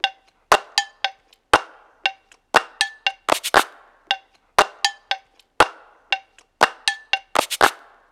Haze Perc Loop.wav